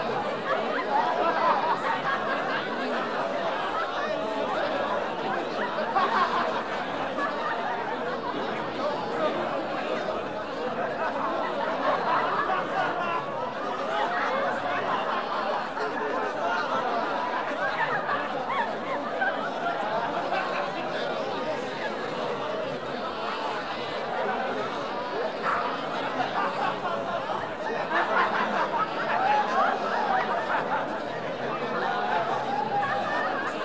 0031_循环音_欢声笑语.ogg